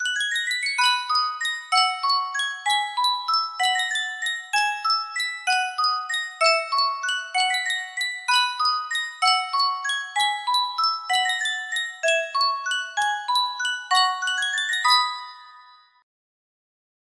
Sankyo Music Box - Tchaikovsky Waltz of the Flowers QM music box melody
Full range 60